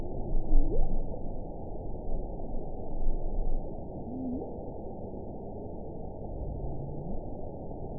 event 919327 date 12/30/23 time 18:05:31 GMT (1 year, 5 months ago) score 6.42 location TSS-AB07 detected by nrw target species NRW annotations +NRW Spectrogram: Frequency (kHz) vs. Time (s) audio not available .wav